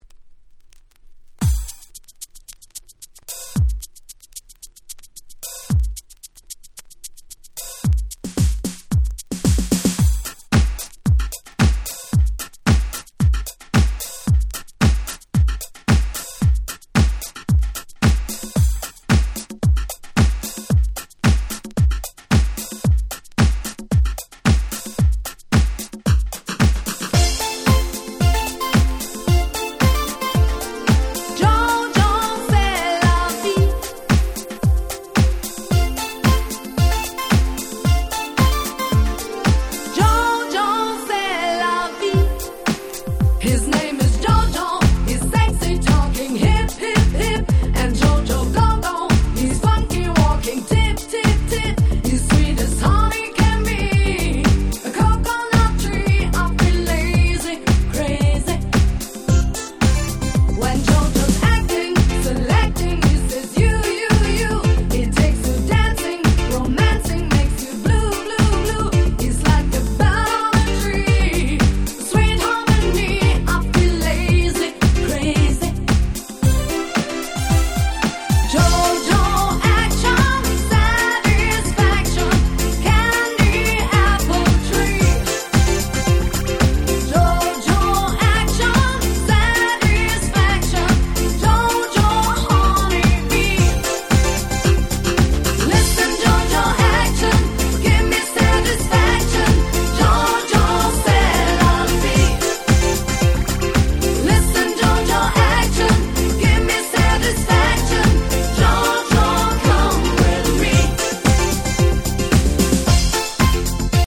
97' Smash Hit Dance Pop !!
いわゆる「ADM Beat」のキャッチーダンスポップ !!
Euro Dance